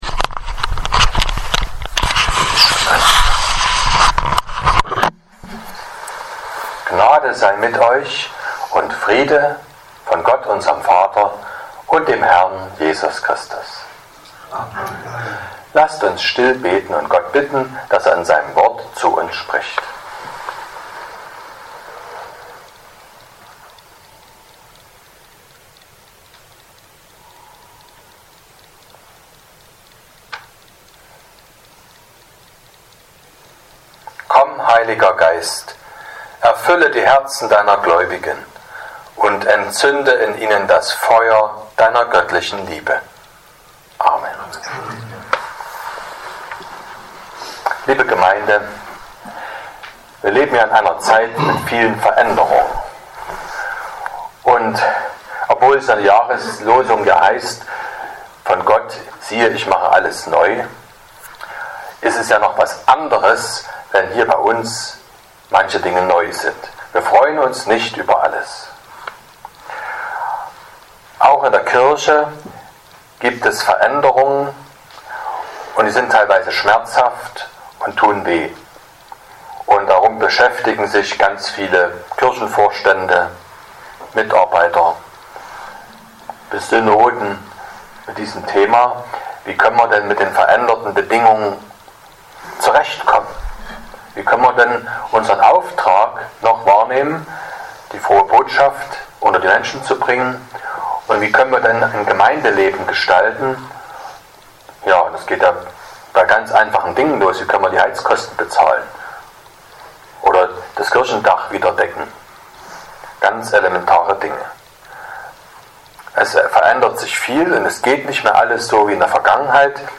01.02.2026 – Gottesdienst
Predigt (Audio): 2026-02-01_Auftrag_-_Gewohnheit__Predigtreihe_2026__Thema_3_.mp3 (25,6 MB)